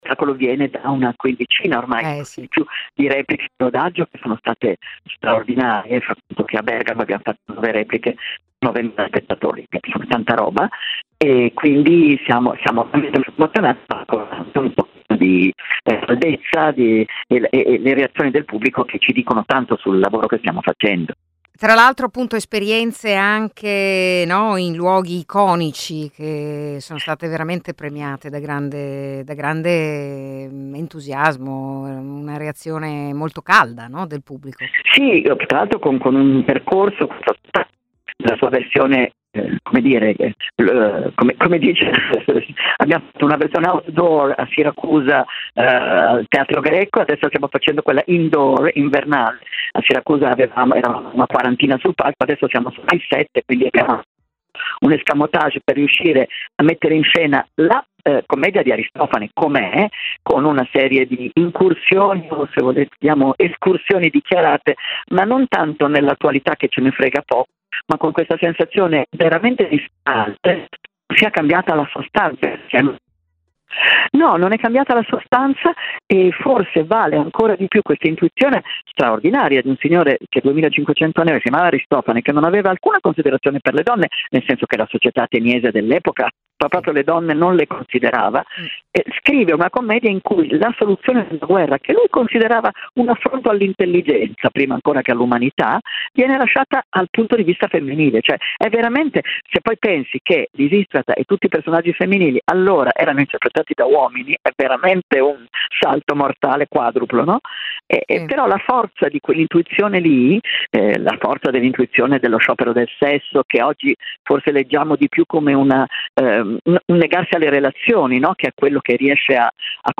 L'intervista